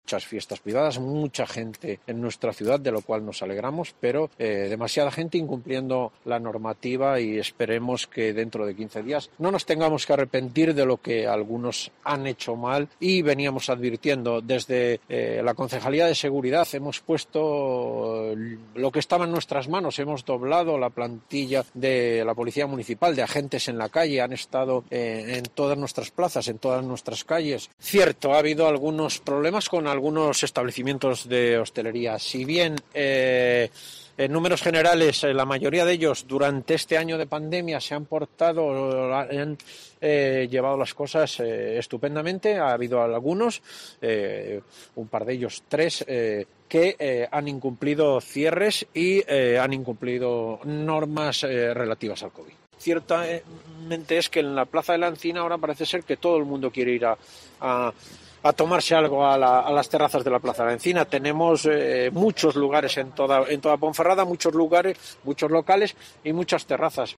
Escucha aquí al concejal de Seguridad de la capital berciana, José Antonio Cartón